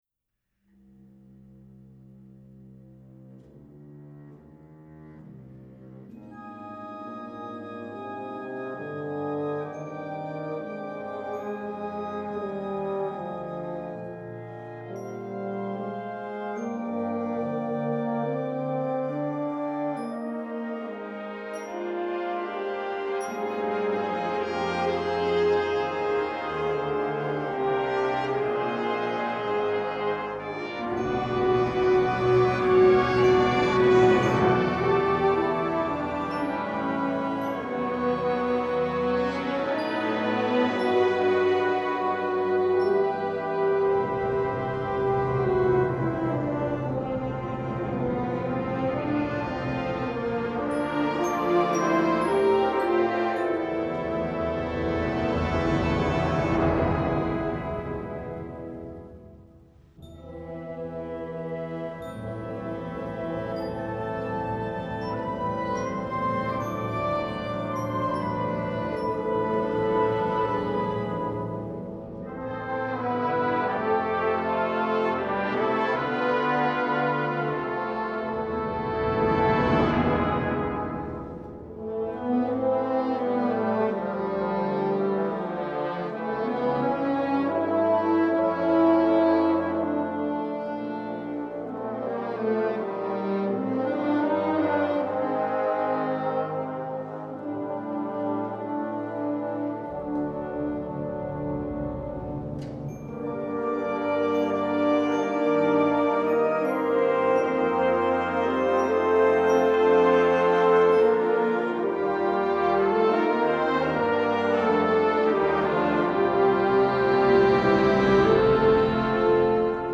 Genre: Wind Orchestra
low chant -like lines